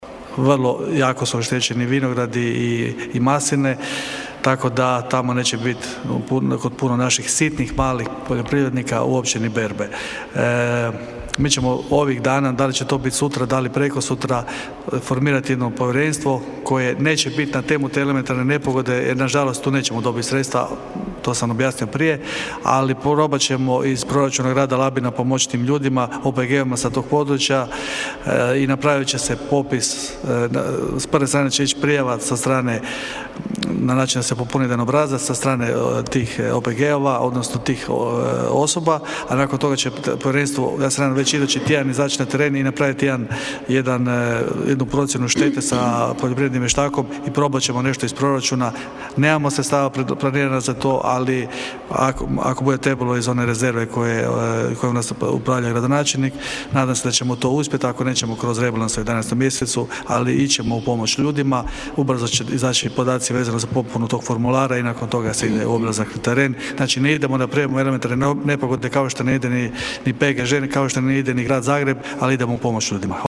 Na današnjoj sjednici Gradskog vijeća Labina gradonačelnik Valter Glavičić kazao je kako nisu ispunjeni uvjeti  za proglašenje elementarne nepogode, no da će Grad pomoći poljoprivrednicima: (